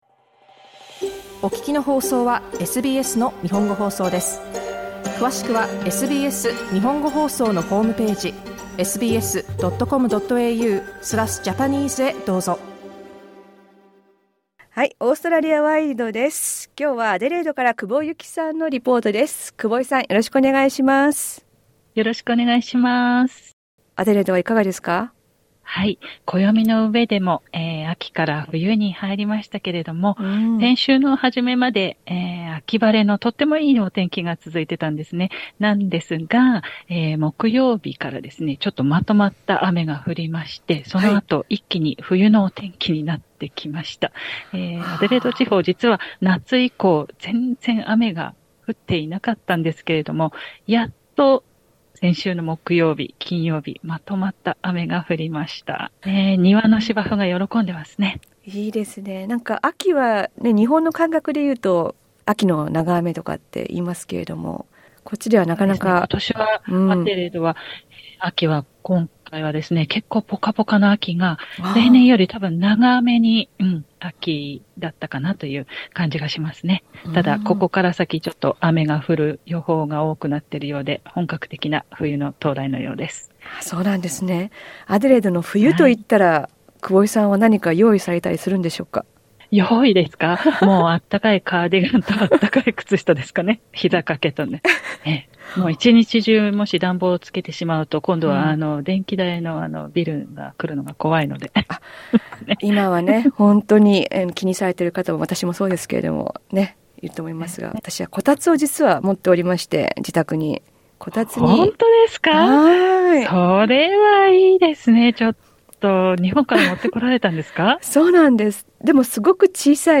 SBS Japanese